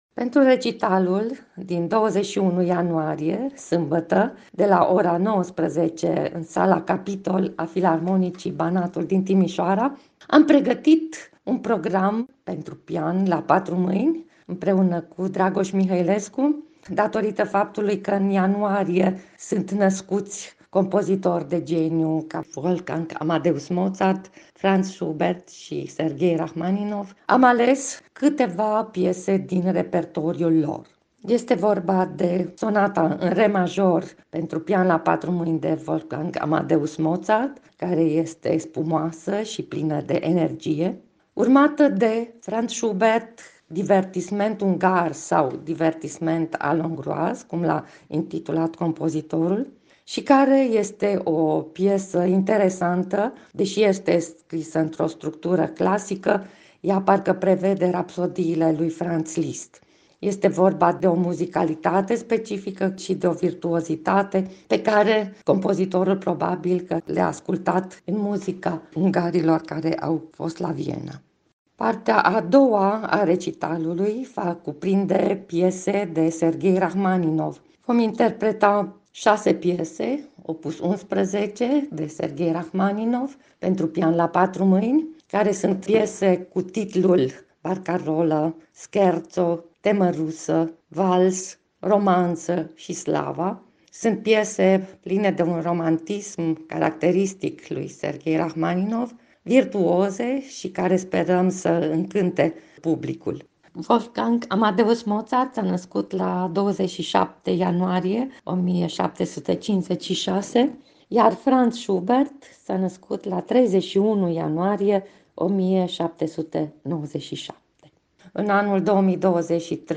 interviu pentru Radio Timișoara